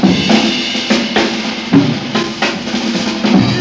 JAZZ BREAK 2.wav